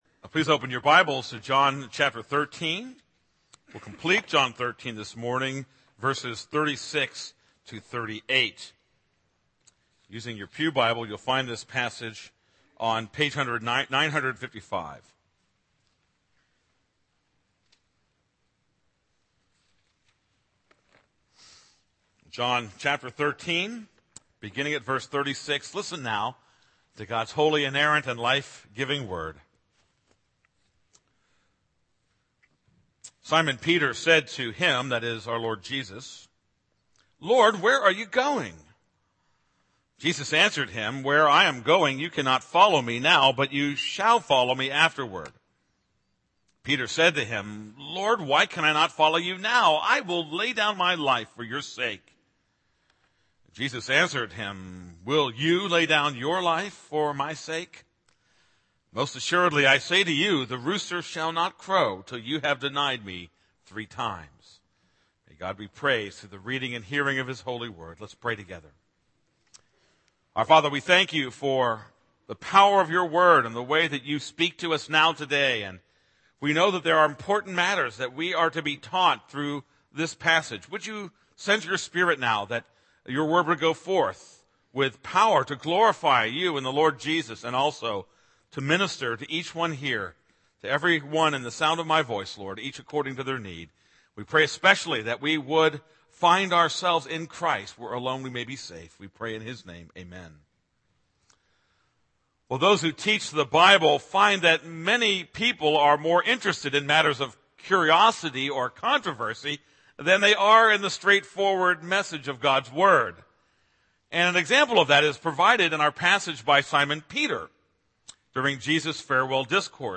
This is a sermon on John 13:36-38.